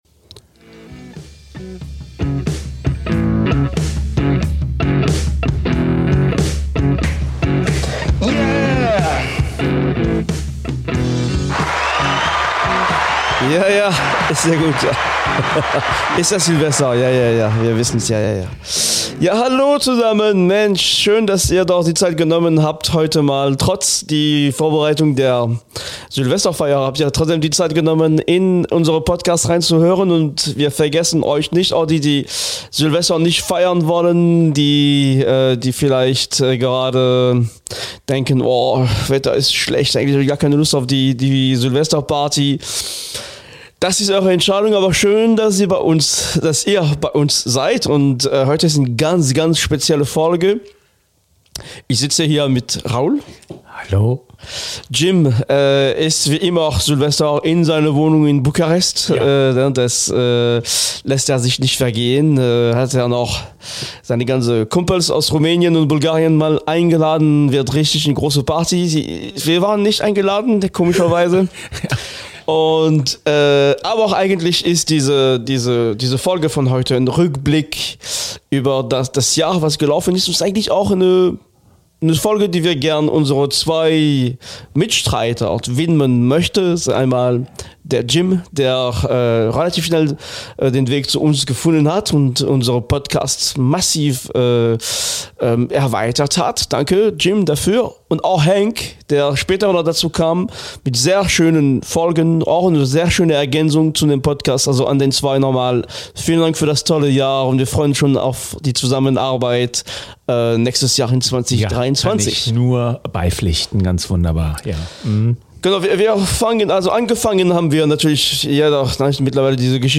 Und Musik gibt es natürlich auch!